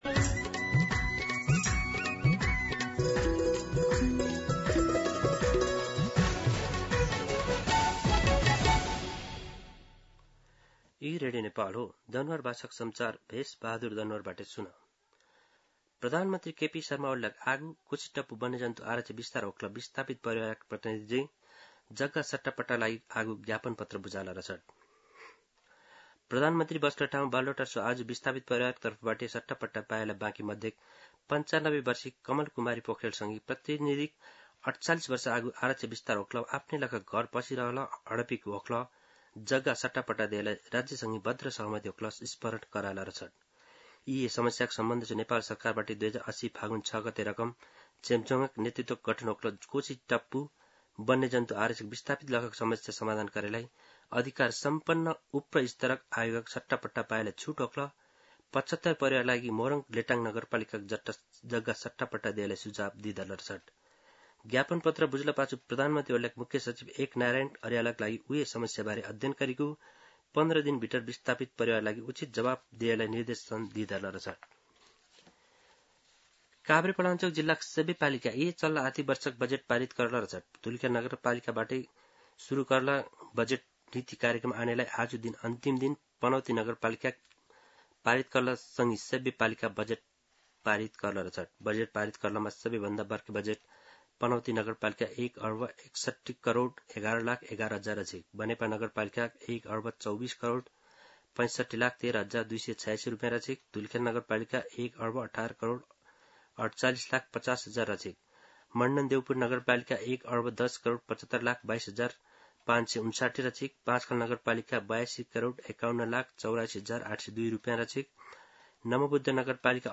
An online outlet of Nepal's national radio broadcaster
दनुवार भाषामा समाचार : ११ असार , २०८२
Danuwar-News-3-11.mp3